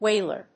音節whál・er 発音記号・読み方
/ˈweɪlɚ(米国英語), ˈweɪlə(英国英語)/